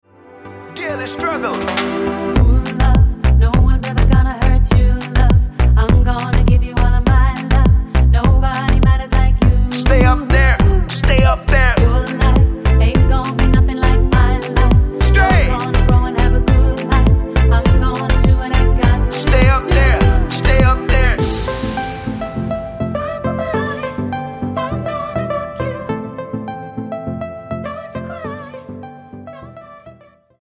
Tonart:Am mit Chor
Die besten Playbacks Instrumentals und Karaoke Versionen .